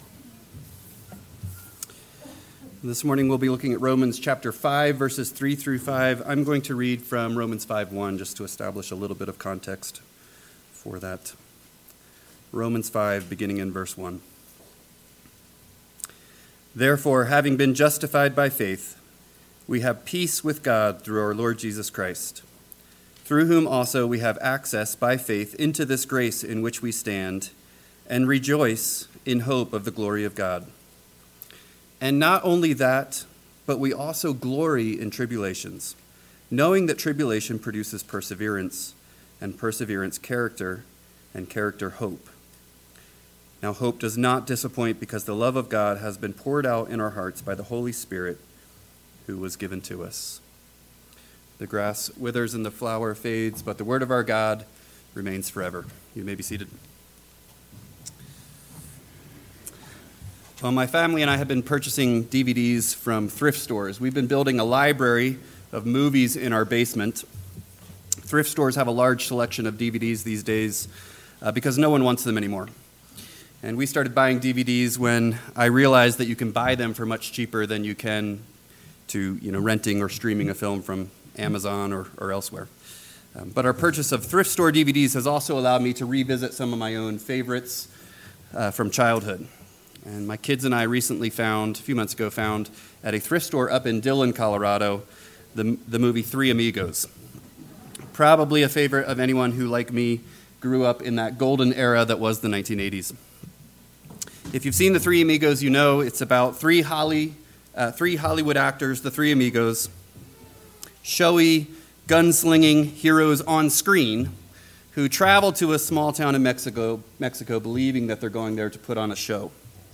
AM Sermon – 9/15/2024 – Romans 5:3-5 – Northwoods Sermons